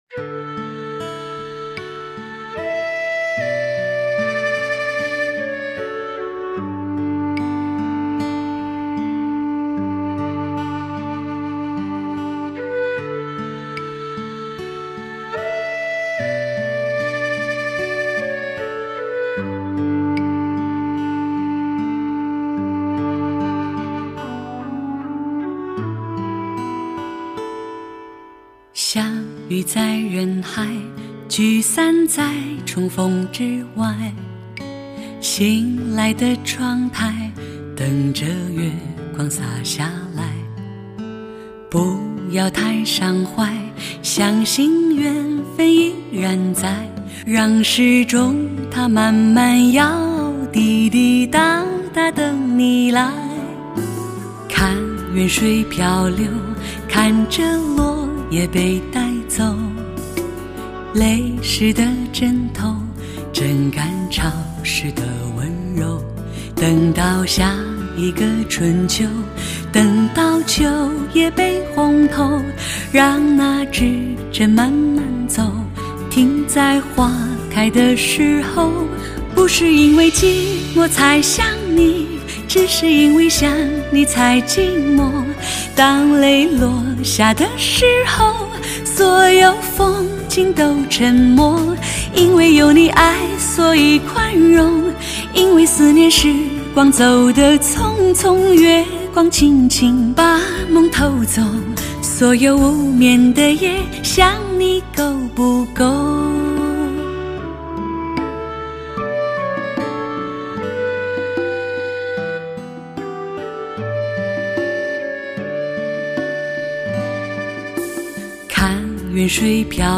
温婉动听。
“没有划痕”的声音，曼妙而唯美，把爱的感觉细细道来，那近乎完美的意境。